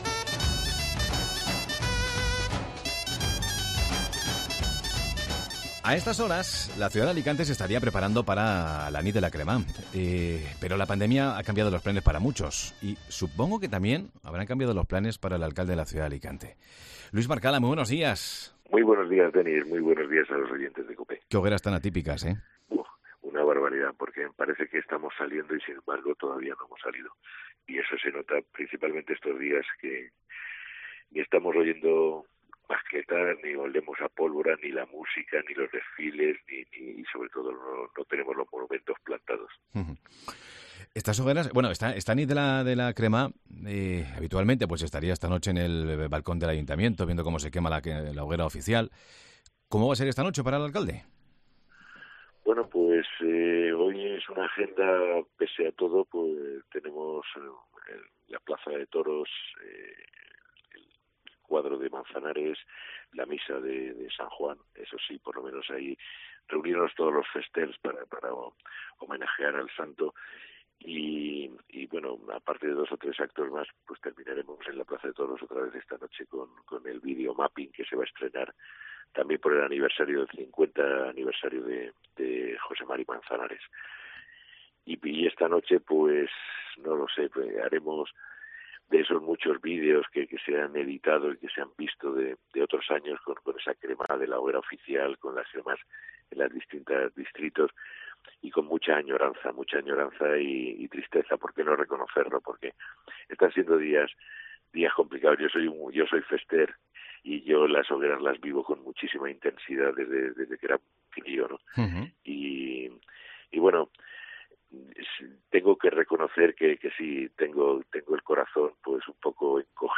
AUDIO: Luis Barcala, alcalde de Alicante, cuenta en Mediodía COPE Alicante los dos deseos para la noche de San Juan